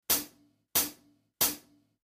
Комплект тарелок Turkish M-Set 2 в каталоге Asia Music
Хай-хэт - 14 дюймовКрэш - 18 дюймовРайд - 20 дюймов